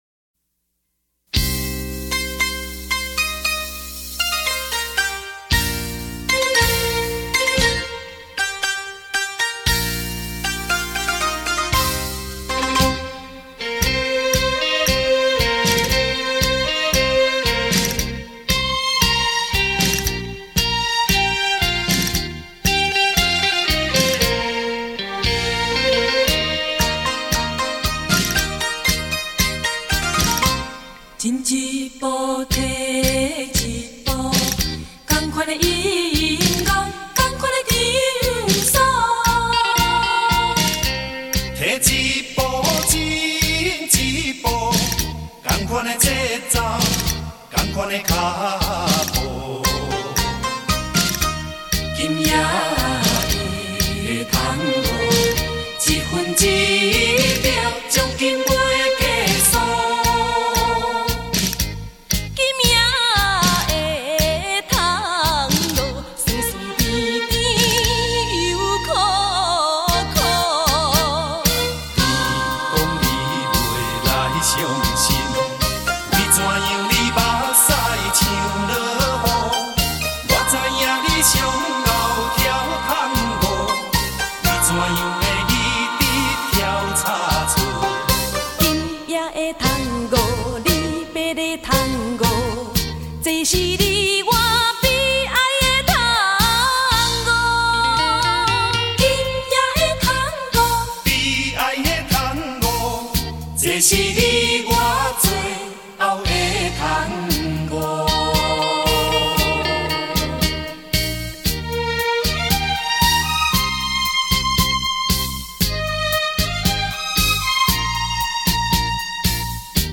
怀旧的歌声